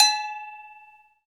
PRC XAGOGO05.wav